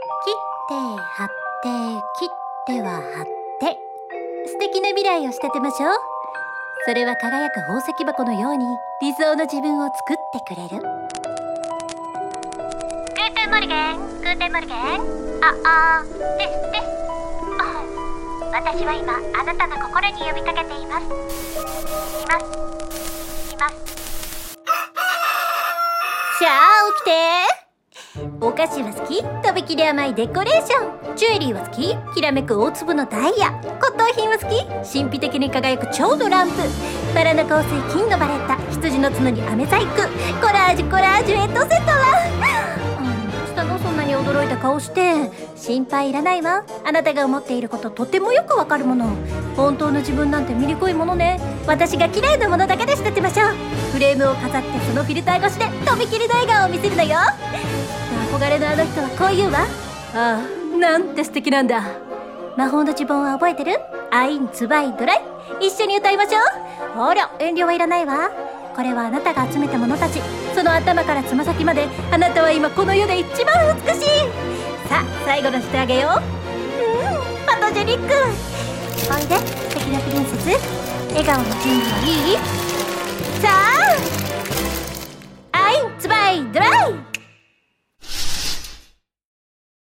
CM風声劇「クライン・レーヴェンの仕立師」